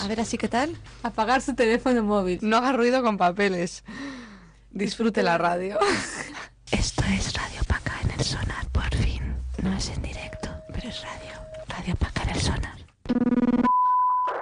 Identificació del programa fet amb motiu del Festival Sonar, a Barcelona